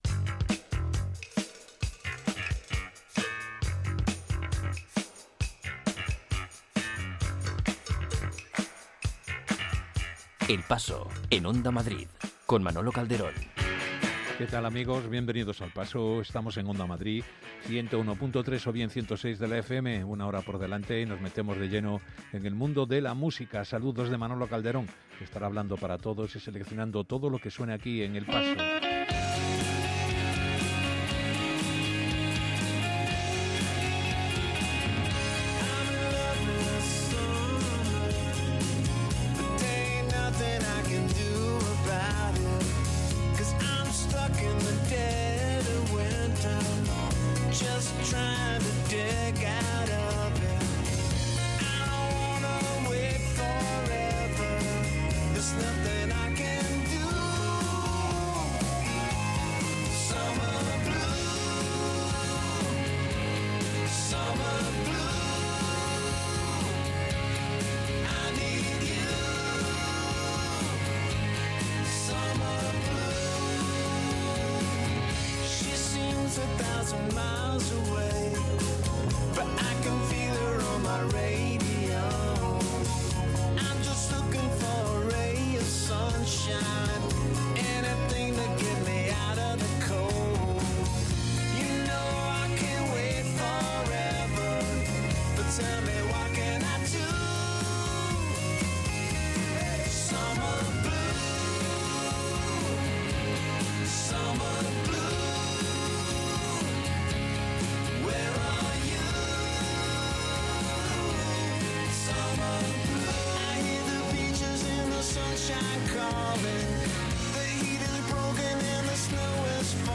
No hay década mala en el repaso musical que hace El Paso, desde el primigenio rock de los años 50 hasta el blues contemporáneo, pasando por el beat, power pop, surf, punk, rock o música negra.